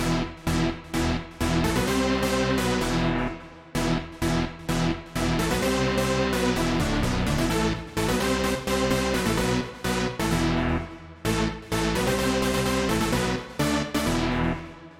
描述：它是FL工作室制作的一种不错的电源线声音合成器
Tag: 电力 - 舞蹈 音乐 低音 合成器 众议院